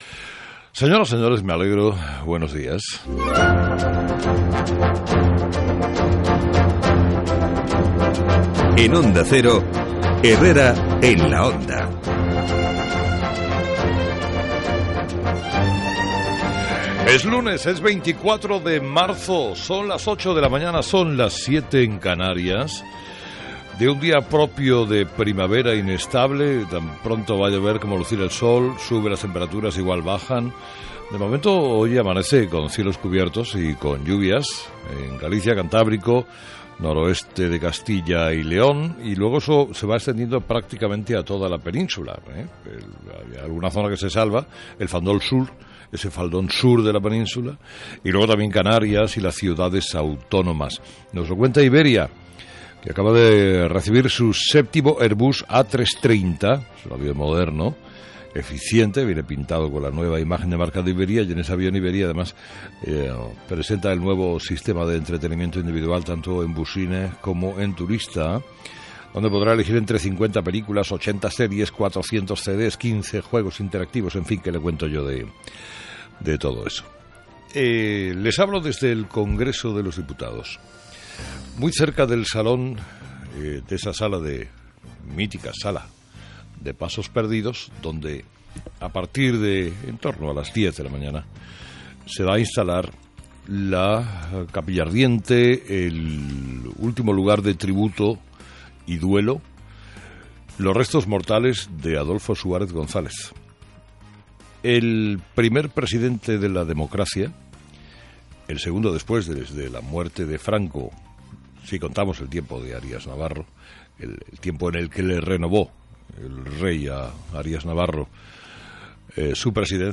24/03/2014 Editorial de Carlos Herrera: "Adolfo Suárez fue una figura de una talla política enorme"